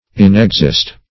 Search Result for " inexist" : The Collaborative International Dictionary of English v.0.48: Inexist \In`ex*ist"\, v. i. [Pref. in- in + exist.] To exist within; to dwell within.